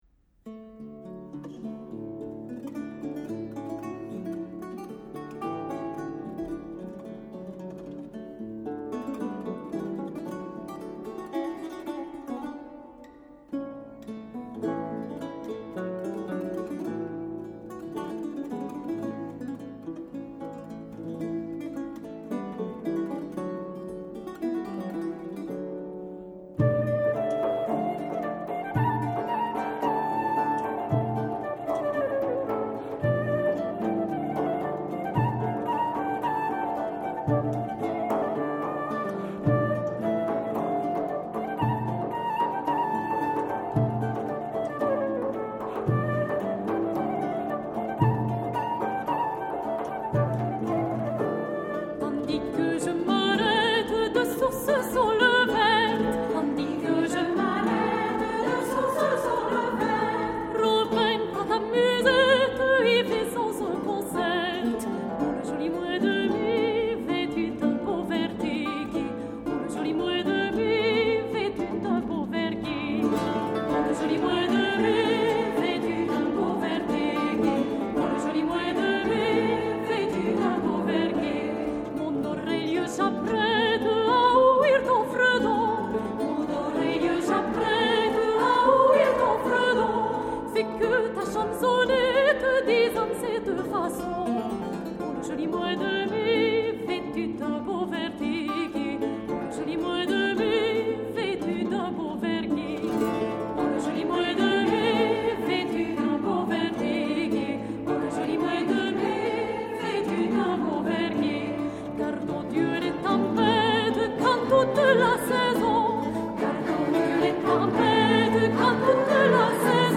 Airs à danser et airs de cour autour de 1600
direction, flûte, musette
violon, alto
viole de gambe
théorbe, luth, guitare
percussions